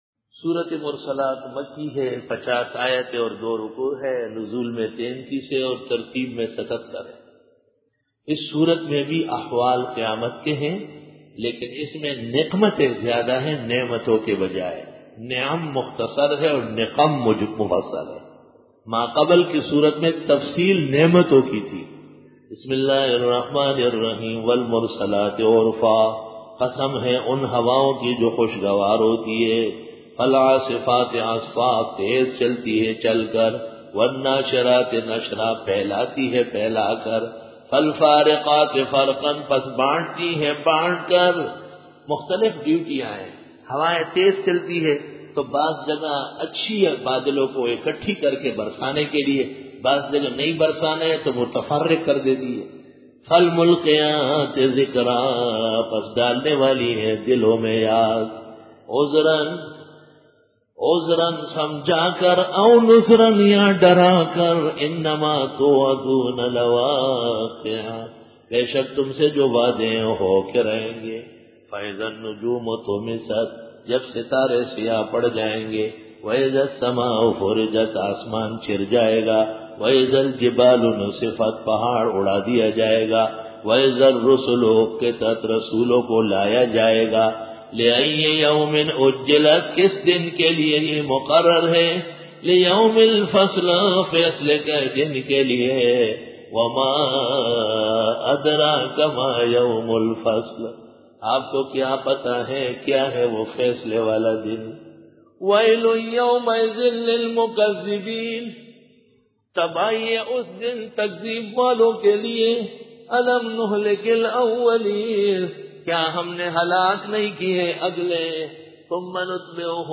Dora-e-Tafseer 2005